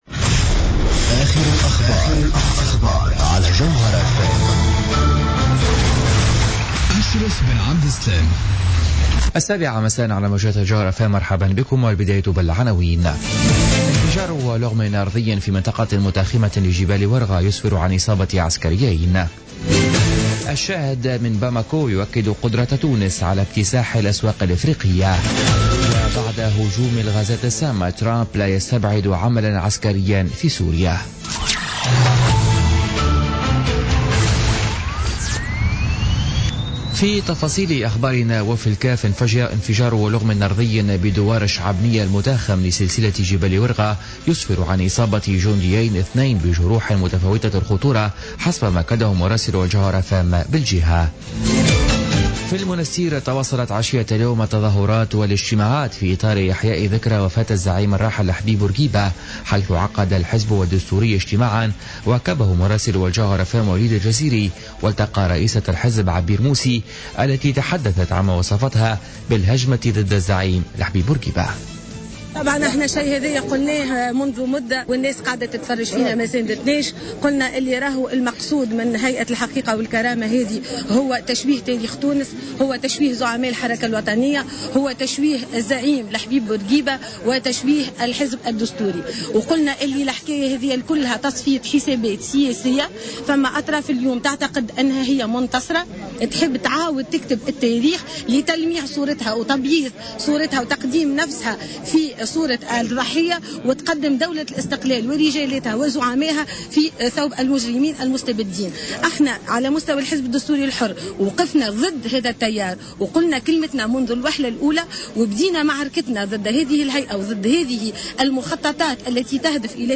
نشرة أخبار السابعة مساء ليوم الخميس 6 أفريل 2017